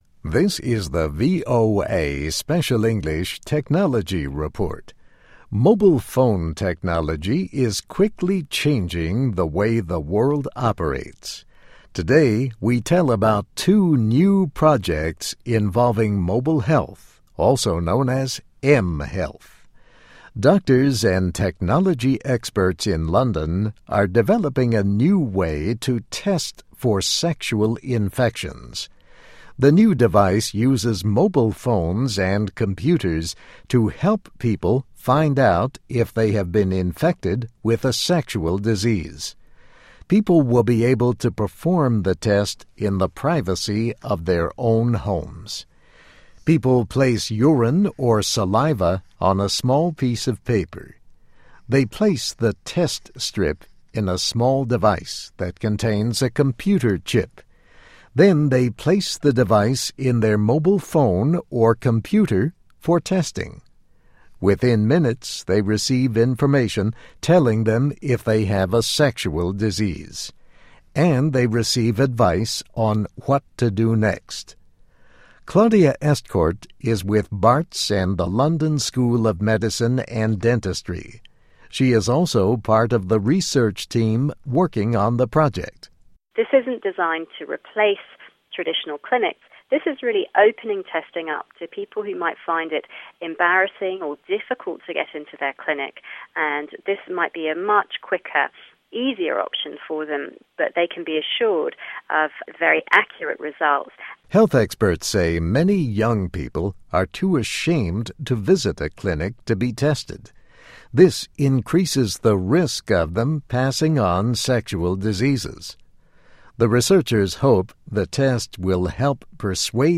Technology Report